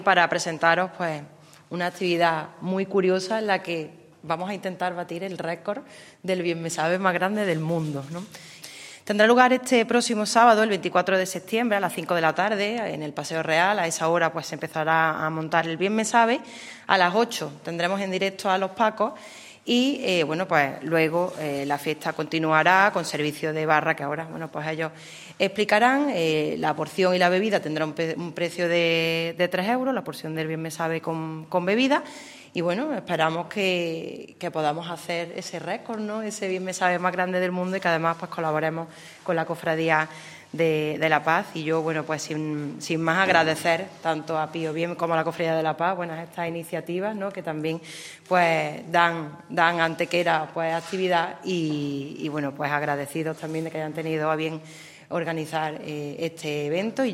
"Se trata de una actividad muy curiosa en la que vamos a tratar de conseguir hacer el bienmesabe más grande del mundo", manifiesta la teniente de alcalde Elena Melero, quien invita a su vez a participar en esta actividad que sirve también para colaborar con una de las cofradías antequeranas más antiguas y que mayor patrimonio artístico atesora.
Cortes de voz